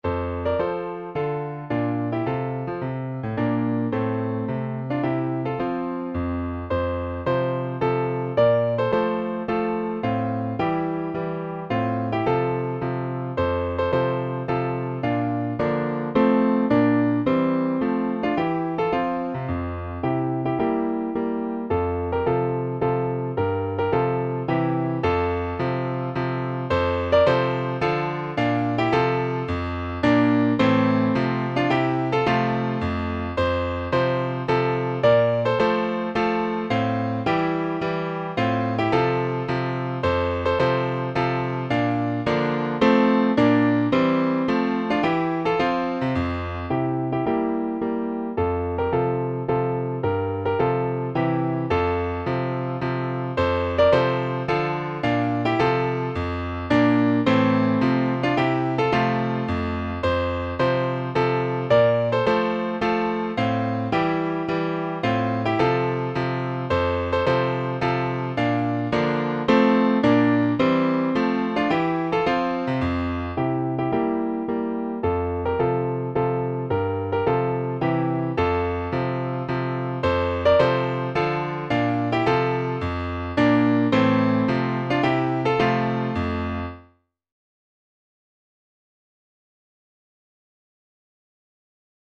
高砂高校柔道部歌（カラオケバージョン）.mp3